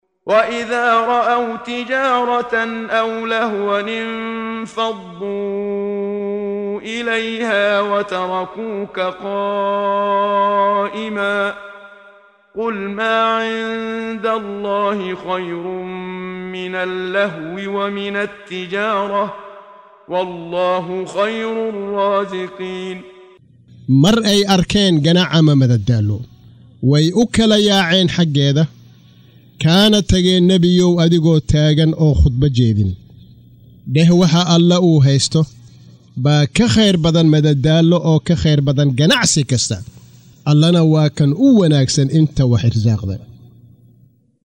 Waa Akhrin Codeed Af Soomaali ah ee Macaanida Suuradda AJumcah ( Jamcada ) oo u kala Qaybsan Aayado